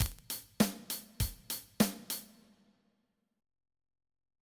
Simple quadruple drum pattern on a rock drum kit.
Simple_duple_drum_pattern.ogg.mp3